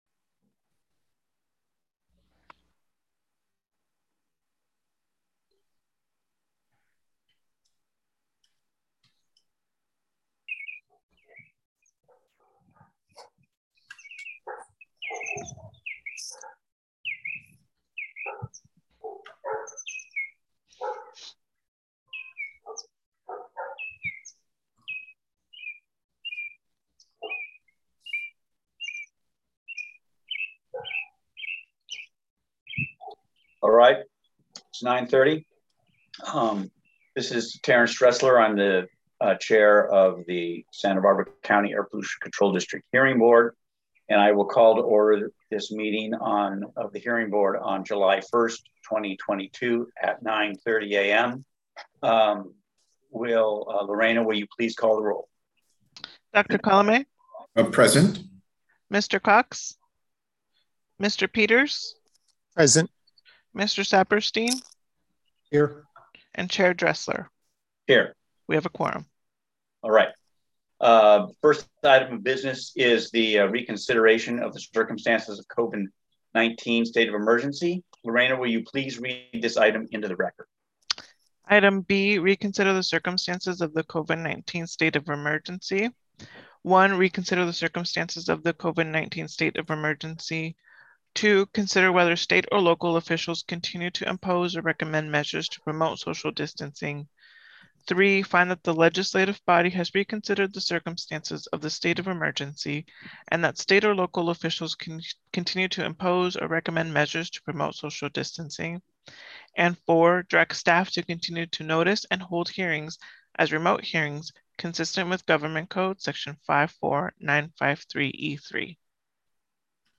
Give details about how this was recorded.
Special Meeting July 1, 2022